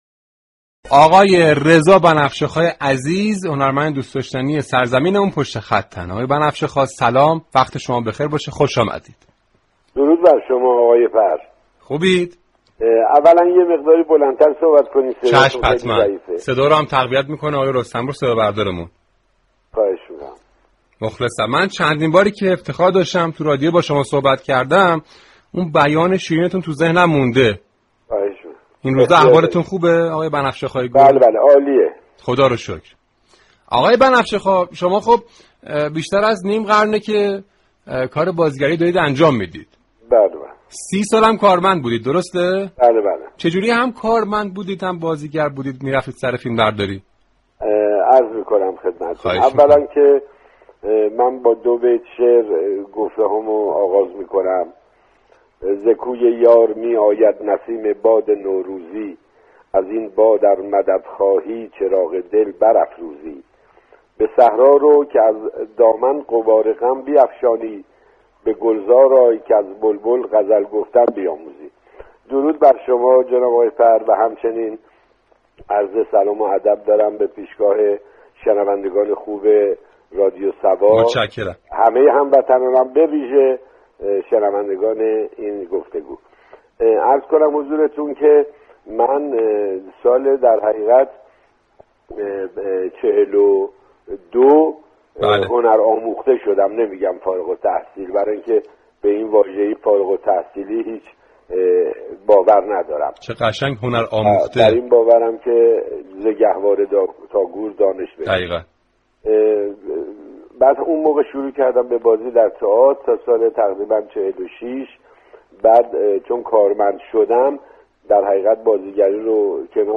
رادیو صبا در برنامه «همپای صبا» میزبان رضا بنفشه خواه بازیگر خوب كشورمان شد.